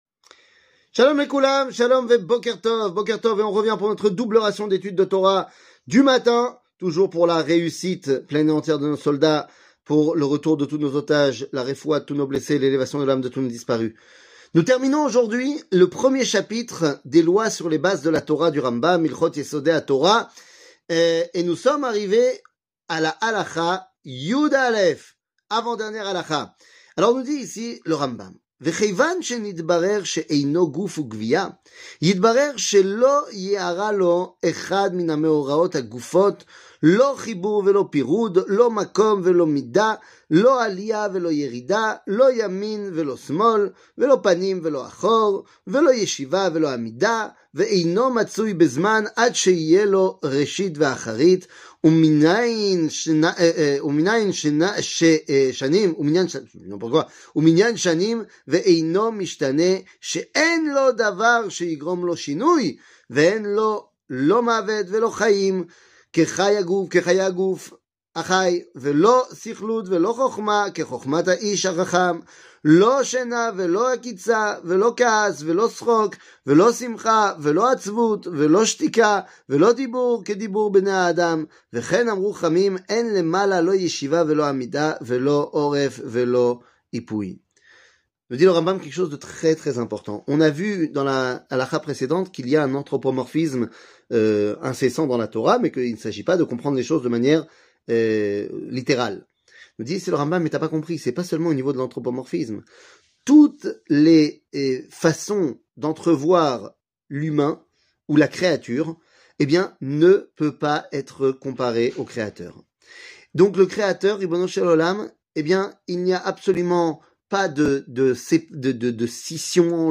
שיעור מ 12 פברואר 2024